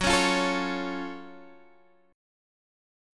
F#mM7 chord